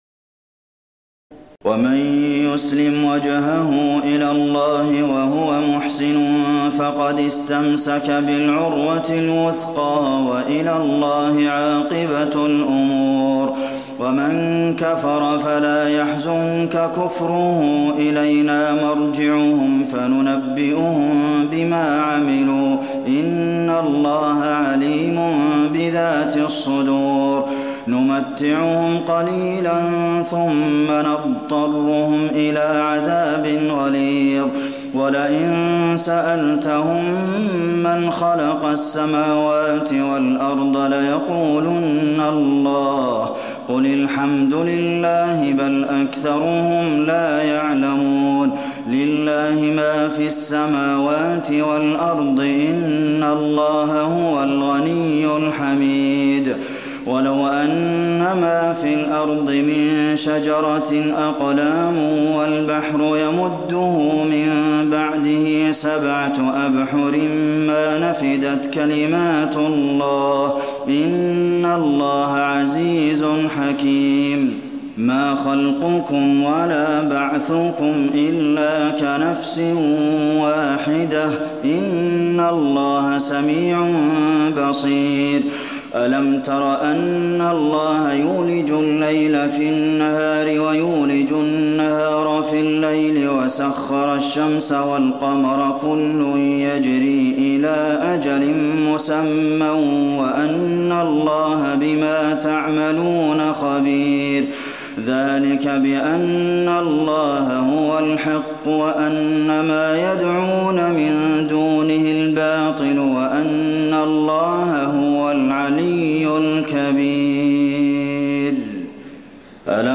تراويح الليلة العشرون رمضان 1426هـ من سور لقمان (22-34) والسجدة و الأحزاب(1-27) Taraweeh 20 st night Ramadan 1426H from Surah Luqman and As-Sajda and Al-Ahzaab > تراويح الحرم النبوي عام 1426 🕌 > التراويح - تلاوات الحرمين